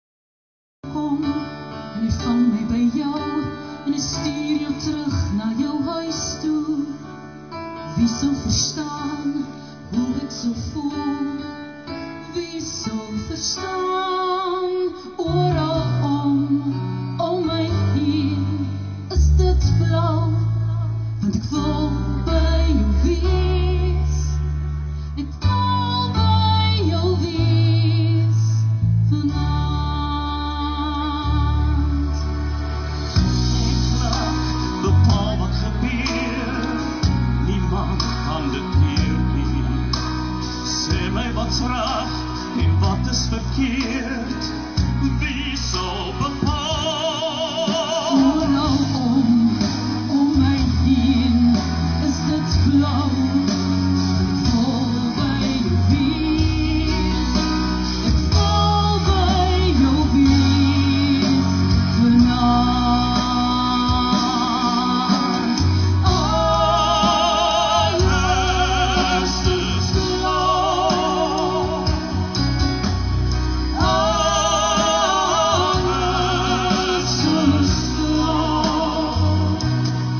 Dit was Afrikaans opppie veld - die Tiere se jaarlikse piekniek-styl musiekaand, waar almal 'n bietjie op die rugbyveld kon uitspan en luister hoe die Tiere musiek maak.